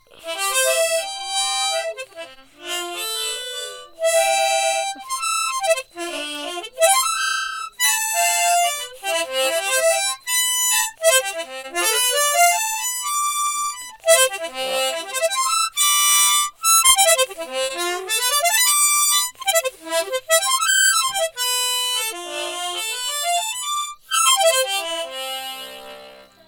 하모니카.ogg